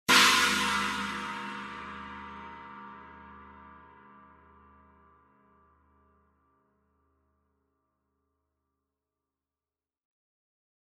The Zildjian 18 A Custom China Cymbal is fast, explosive sound with immediate attack and smooth decay.
Crash Inverted